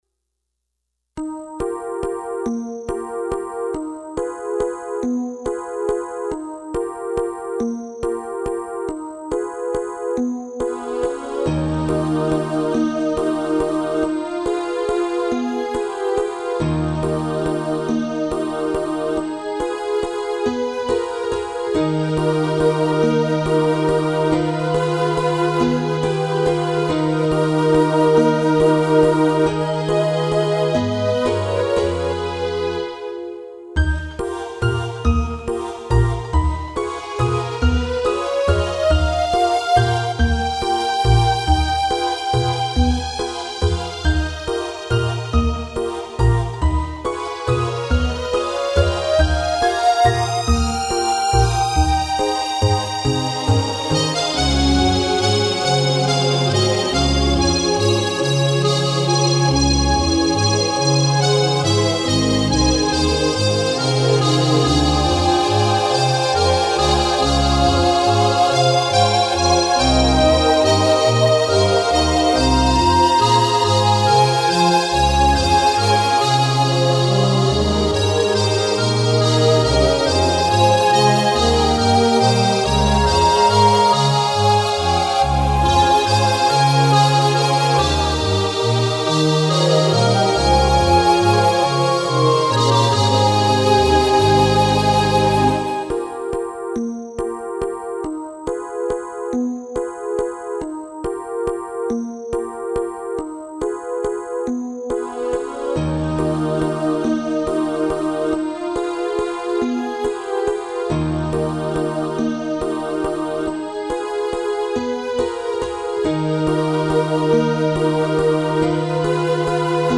こちらでは、ゲーム内で使われているＢＧＭを何曲か、お聴かせしちゃいます。
落ち着いた午後のイメージです。